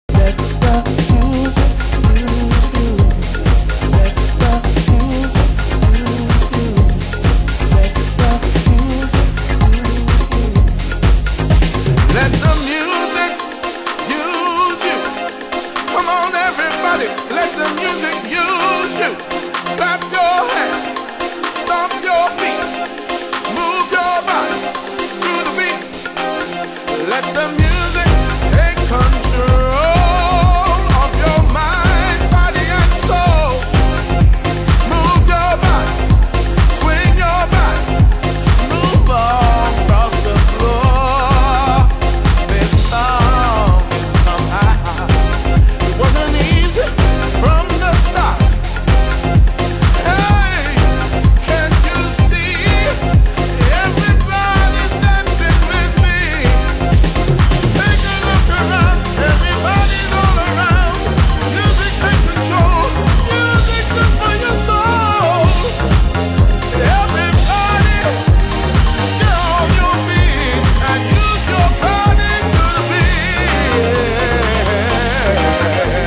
○ベースラインを太く仕上げた、シカゴクラシックスリメイク！(A1)